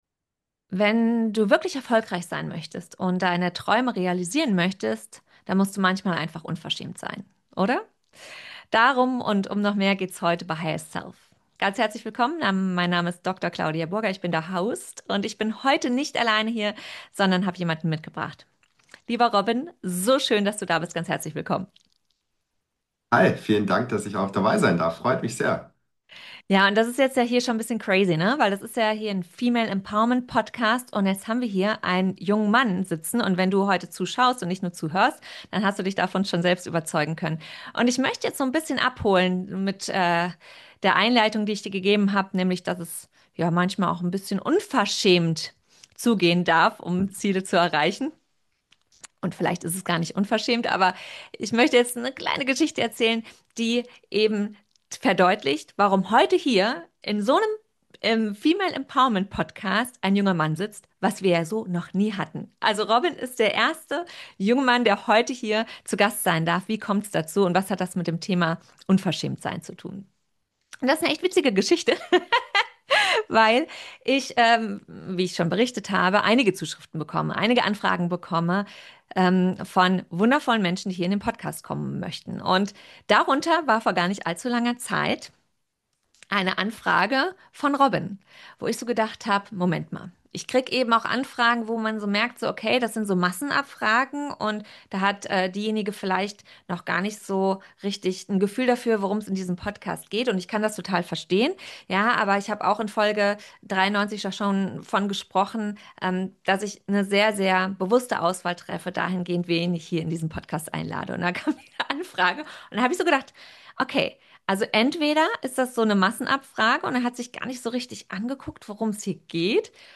Ein Gespräch darüber, warum moderne Führung weniger mit Perfektion und mehr mit innerer Klarheit zu tun hat.